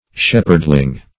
Shepherdling \Shep"herd*ling\, n. A little shepherd.